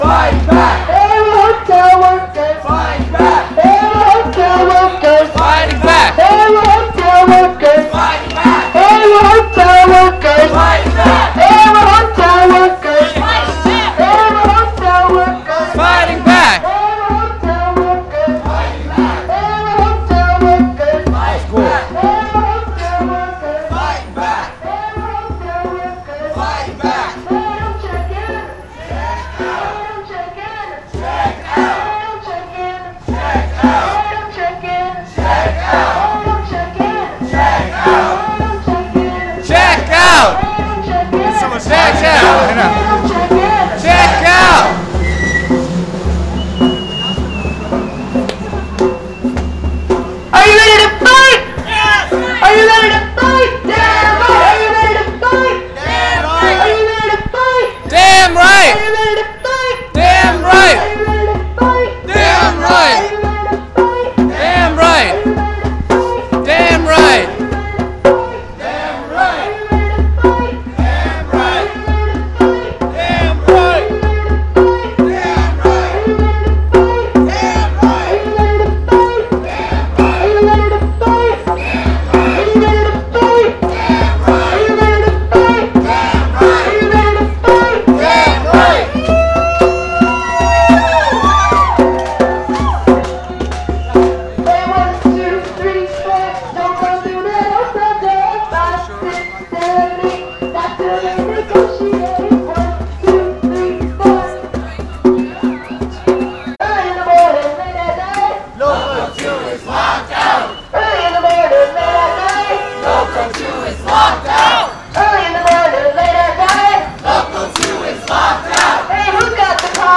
§chants
chants.wav